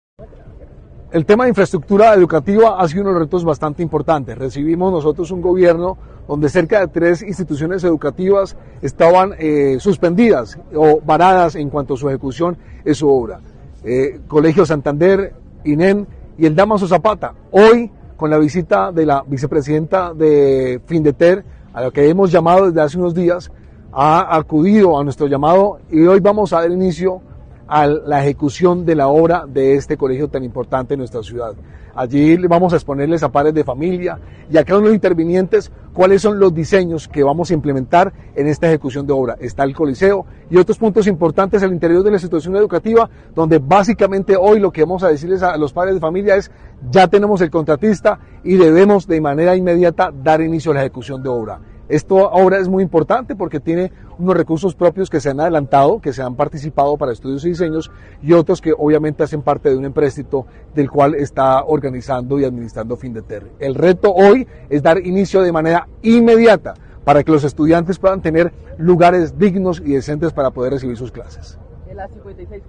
Cristian Portilla, alcalde de Bucaramanga